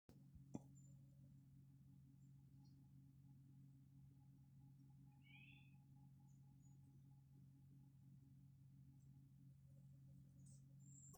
Golden Oriole, Oriolus oriolus
Ziņotāja saglabāts vietas nosaukumsKrāslavas nov., Adamova
StatusSinging male in breeding season